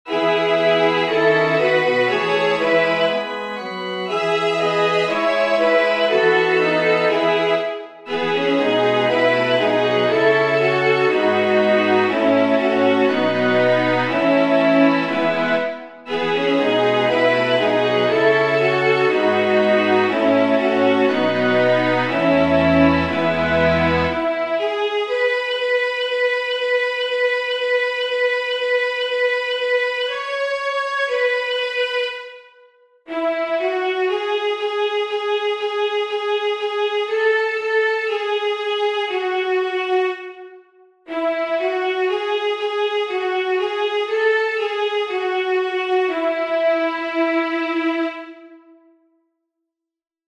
Anthem Umepakiwa na